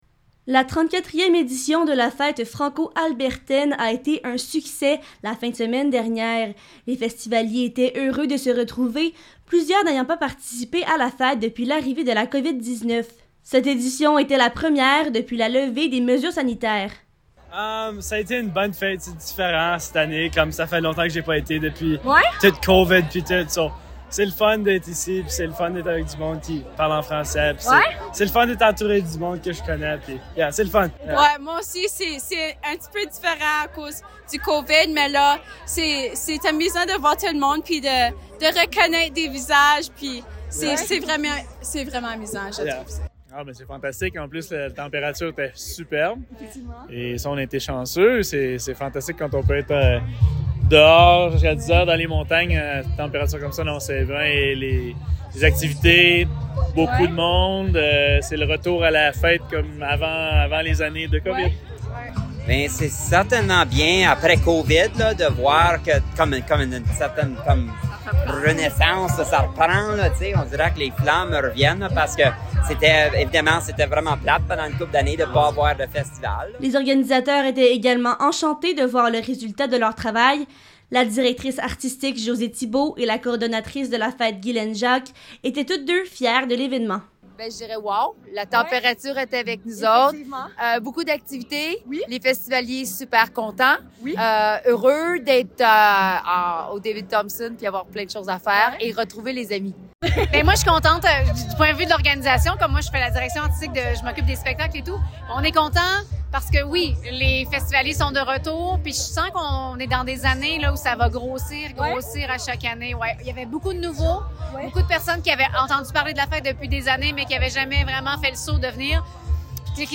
Fete-Franco-Vox-Pop-Reportage.mp3